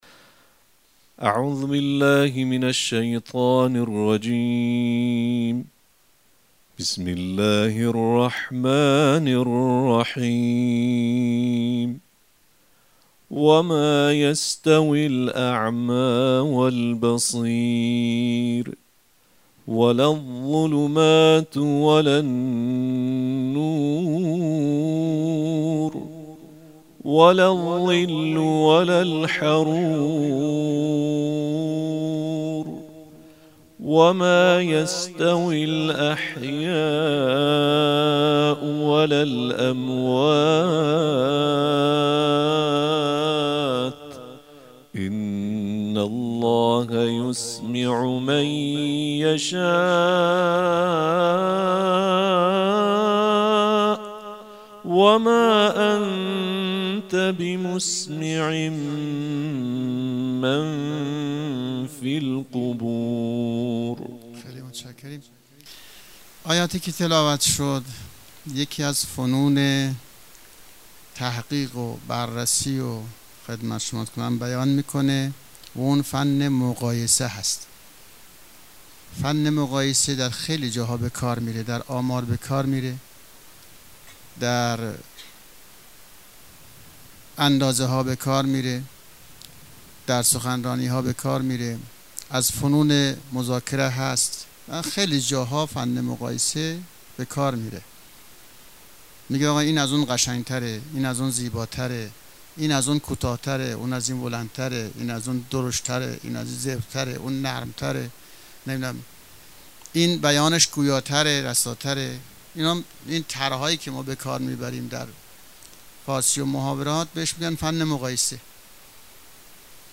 صوت نهمین کرسی تلاوت قرآن کریم